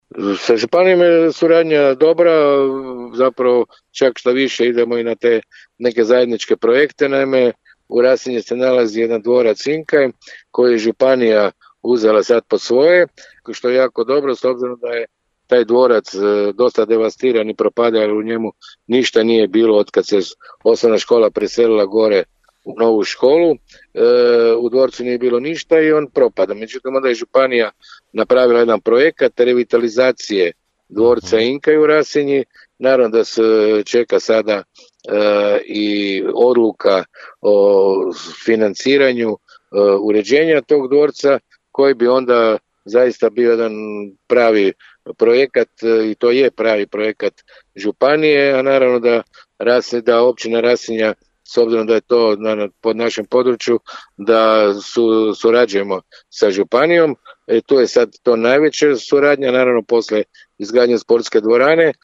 Načelnik općine Rasinja Danimir Kolman bio je gost emisije Susjedne općine Podravskog radija u kojoj je govorio o aktualnom stanju na području općine